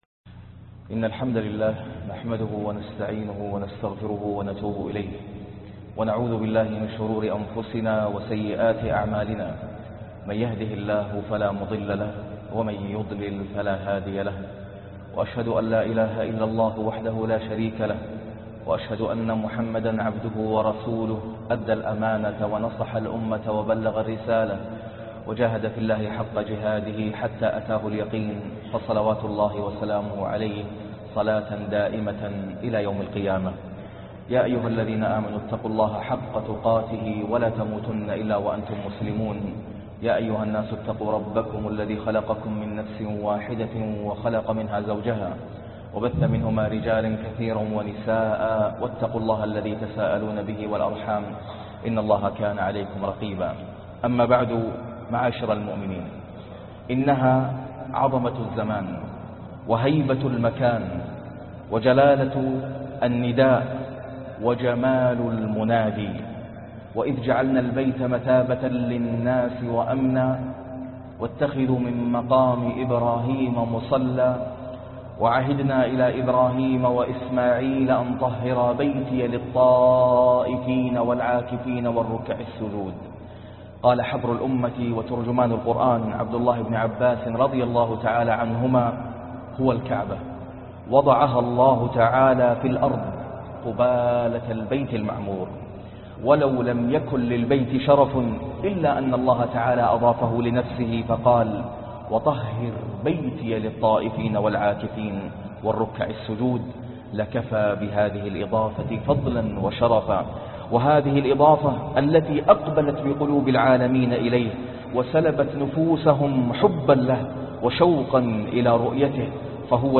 التشويق لحج البيت العتيق - خطبة الجمعة